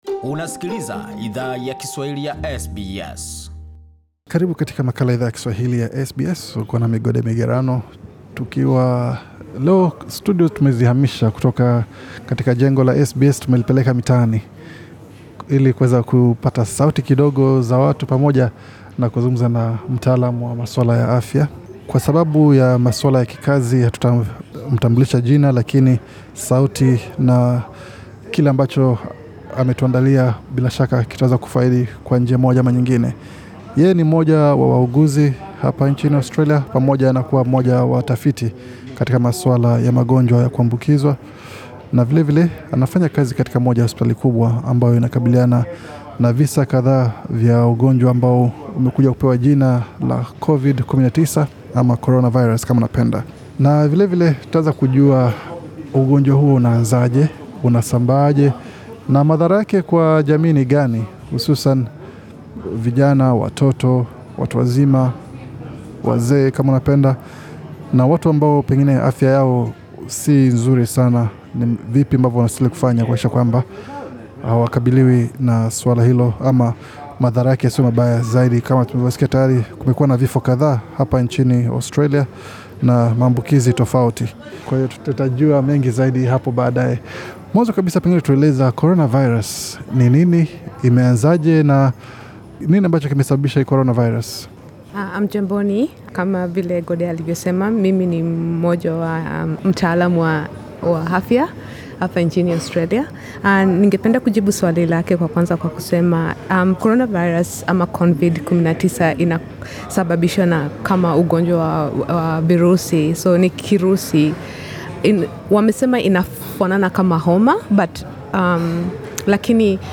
Katika sehemu ya kwanza yamahojiano haya yakujua zaidi kuhusu kiini cha virusi hivi na jinsi yakupata kinga, Idhaa ya Kiswahili ya SBS ilizungumza na mtaalam wa magonjwa yakuambukiza, anaye fanya kazi katika moja ya hospitali kubwa nchini Australia, ambako waathiriwa wa virusi hivyo wanapokea matibabu. Mtaalam huyo aliweka wazi jinsi virusi hivyo huanza, uambukizaji na jinsi yakujikinga na maambukizi ya virusi hivyo vya coronavirus.